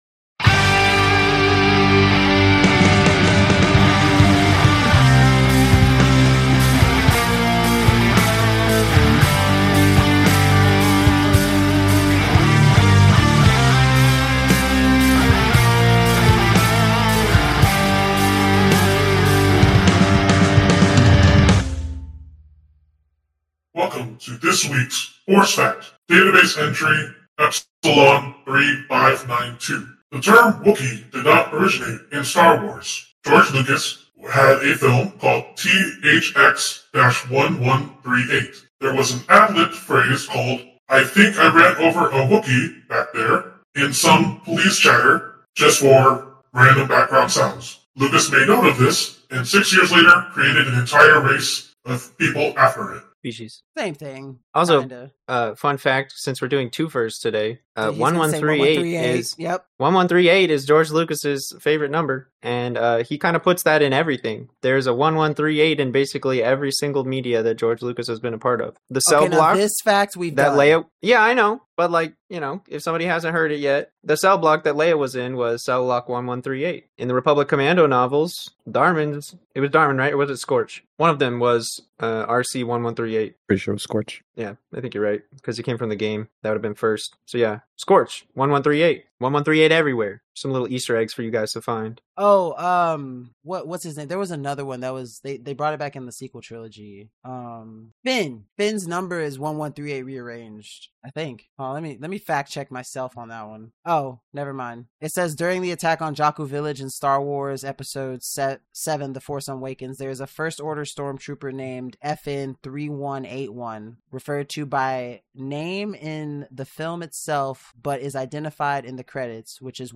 Four friends explore, discover and unravel the mysteries of the Star Wars universe, diving into both the Canon and the Legends timeline to give you all the Star Wars content you never knew you needed.